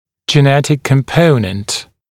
[ʤɪ’netɪk kəm’pəunənt][джи’нэтик кэм’поунэнт]генетический компонент, генетическая составляющая